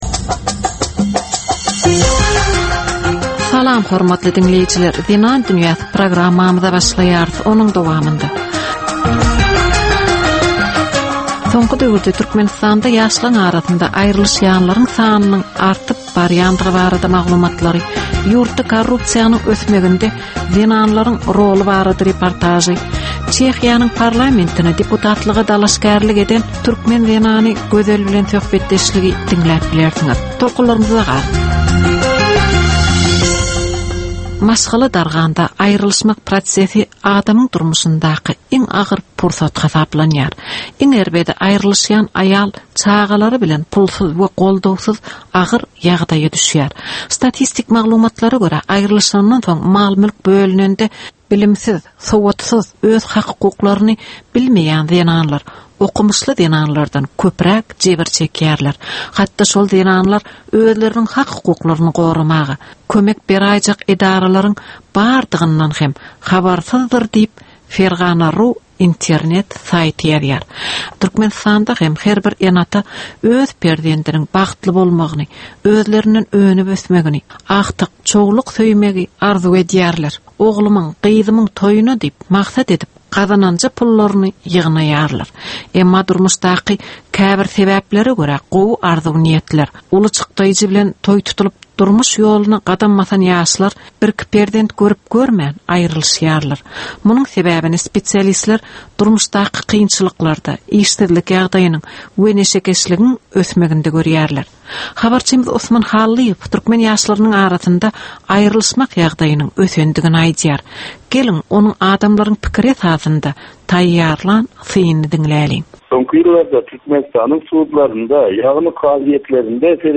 Türkmen we halkara aýal-gyzlarynyň durmuşyna degişli derwaýys meselelere we täzeliklere bagyşlanylyp taýýarlanylýan ýörite gepleşik. Bu gepleşikde aýal-gyzlaryň durmuşyna degişli maglumatlar, synlar, bu meseleler boýunça synçylaryň we bilermenleriň pikrileri, teklipleri we diskussiýalary berilýär.